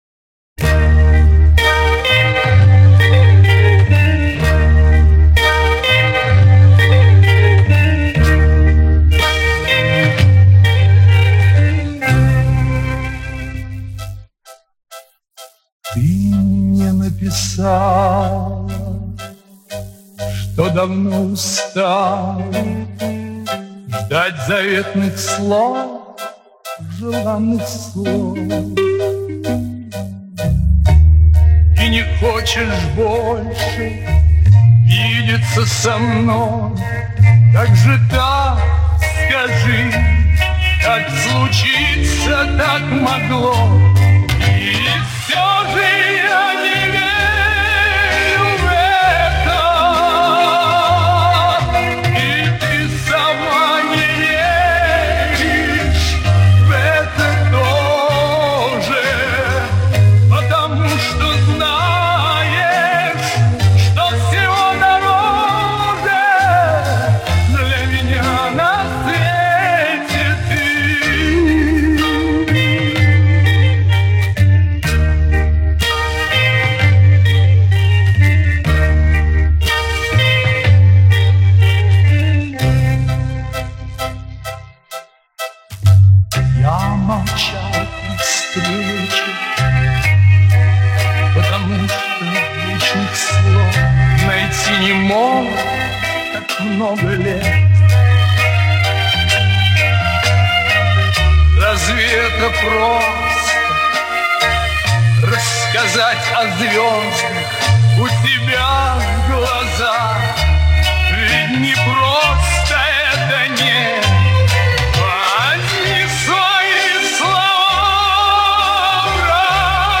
вокальная группа там женская.